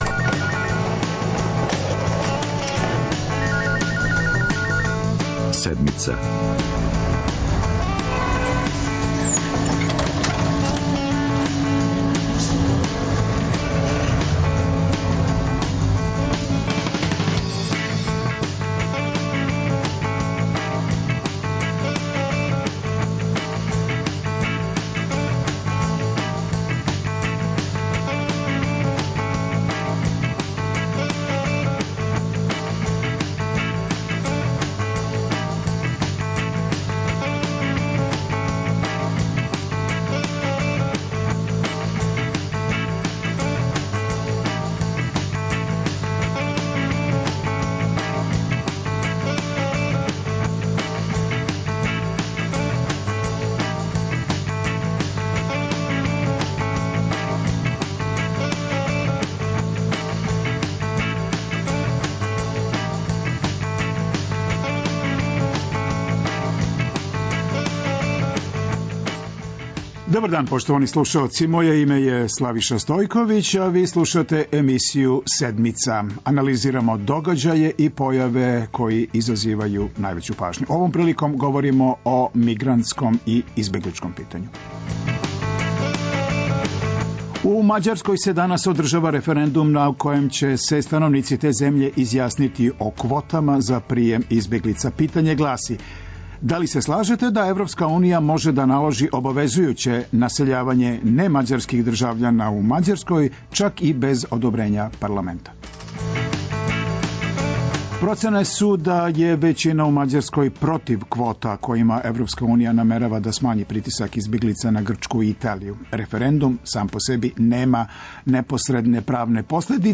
Укључићемо и наше дописнике из иностранства.